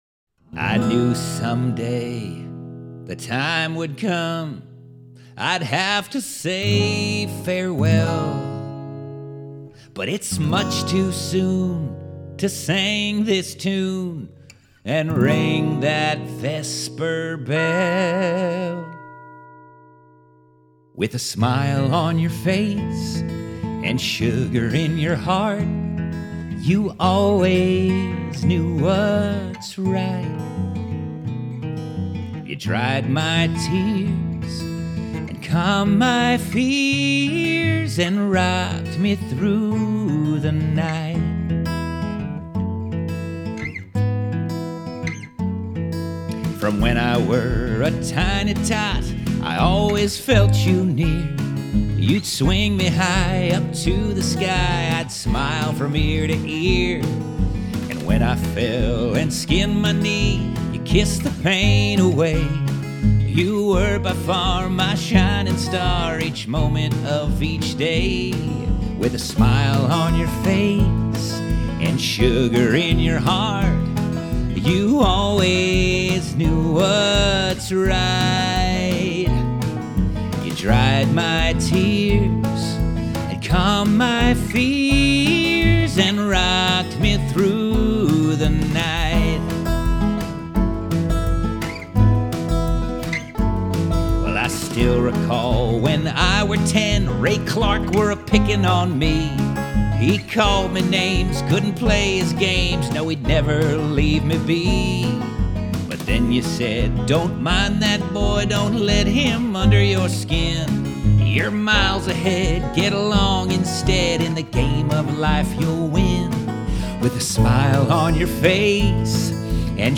Cabaret Songs